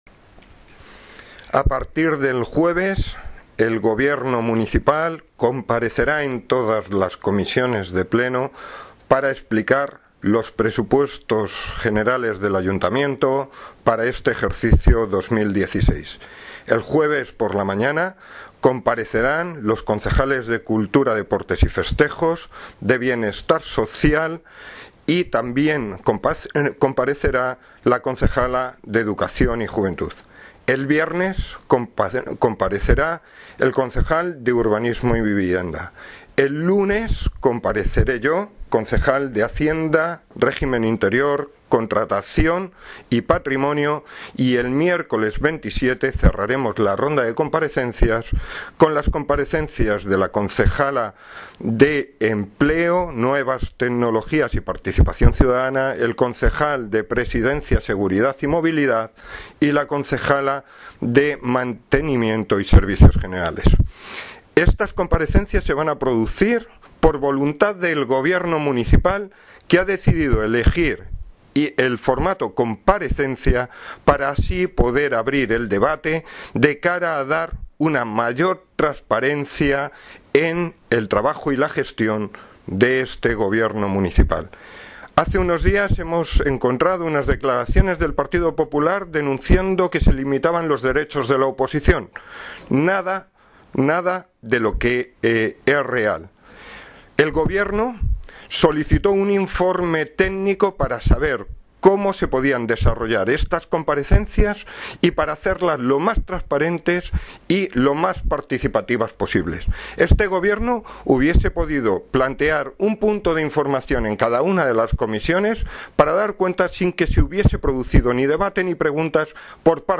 Audio - Javier Gomez (Concejal de Hacienda, Patrimonio, Regimen Interno y Contratacion) Sobre Presupuestos 2016